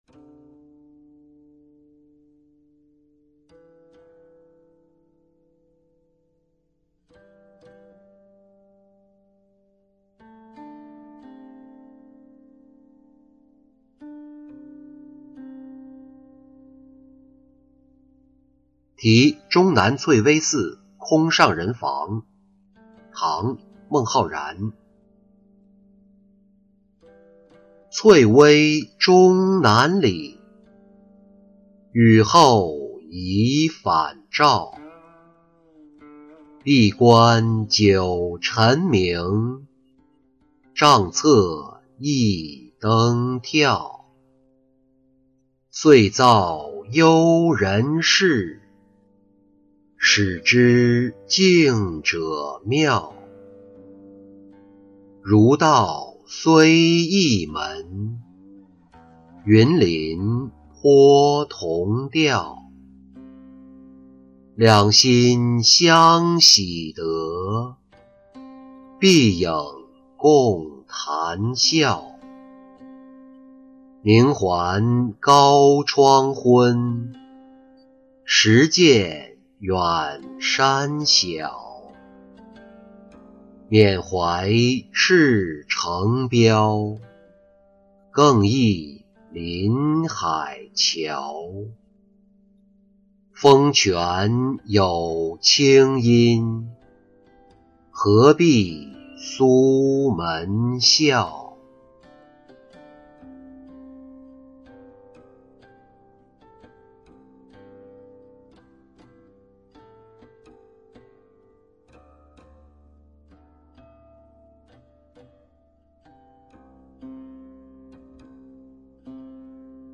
题终南翠微寺空上人房-音频朗读